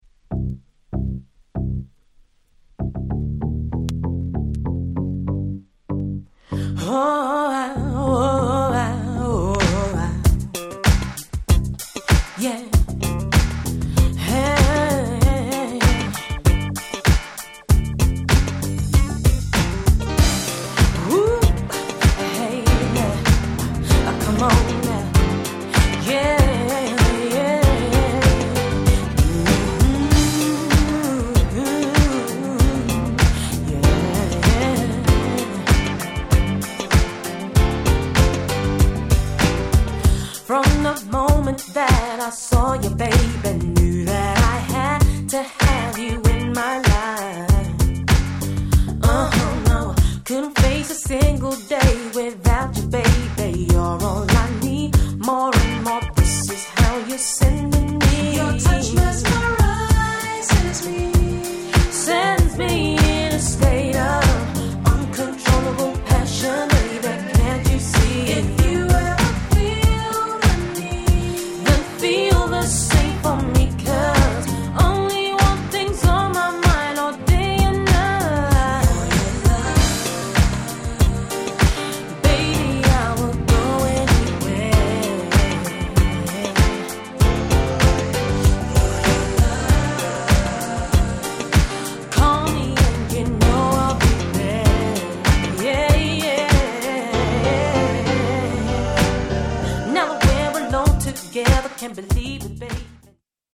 Nice UK Soul♩
爽やかかつ味わい深い極上の一品と言えましょう。